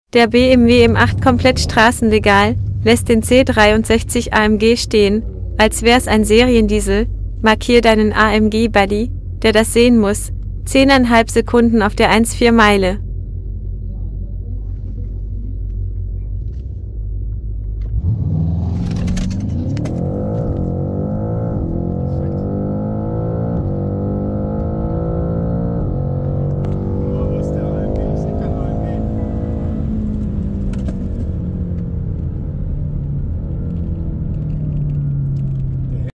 🔥 Der BMW M8 lässt den C63 AMG stehen, als wär’s ein Serien-Diesel!